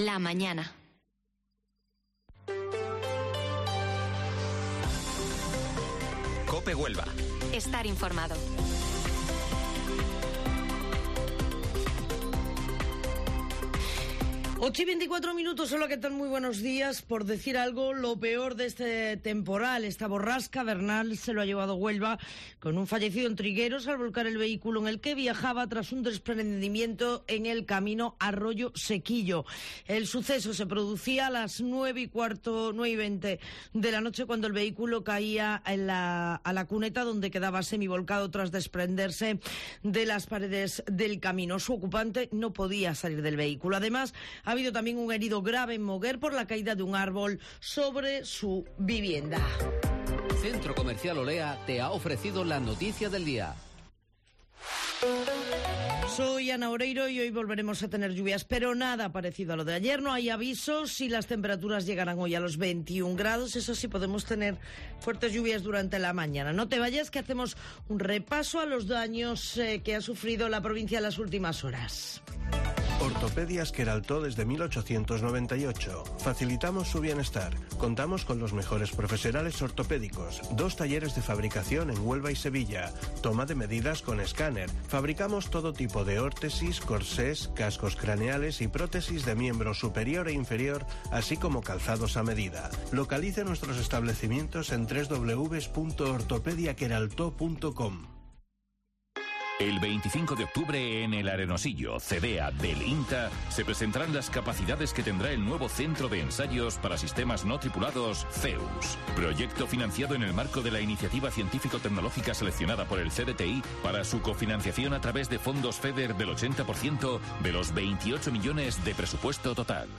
Informativo Matinal Herrera en COPE 23 de octubre